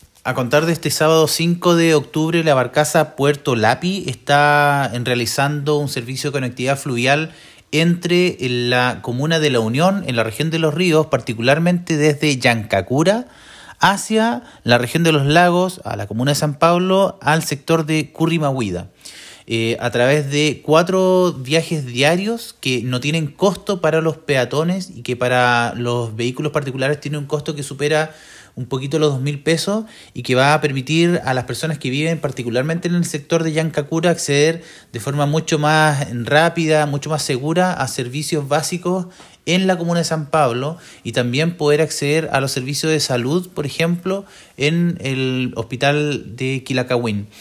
Este servicio permitirá que los vecinos y vecinas puedan transitar entre las comunas de La Unión y San Pablo, con cuatro viajes diarios sin costo para los peatones y para automóviles, por un valor cercano a los dos mil pesos, como señaló el Seremi de Transportes y Telecomunicaciones de Los Lagos, Pablo Joost.